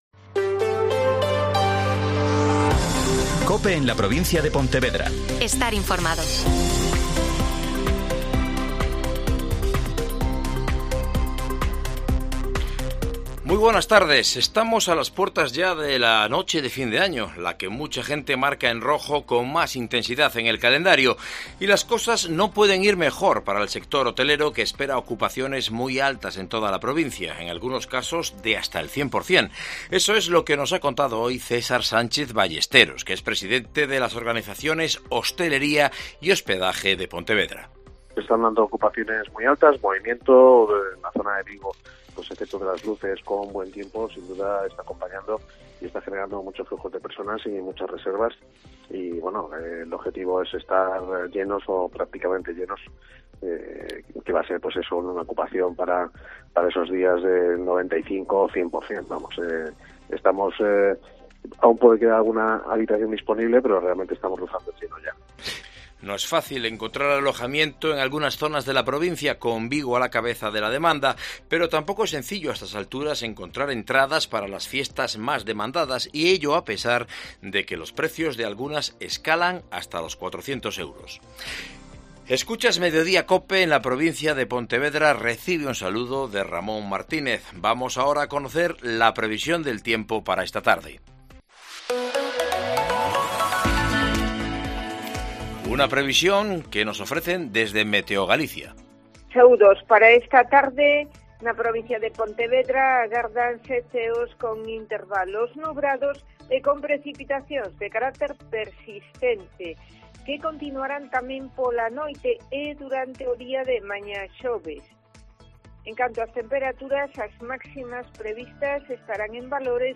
AUDIO: Mediodía COPE Pontevedra y COPE Ría de Arosa (Informativo 14:20h)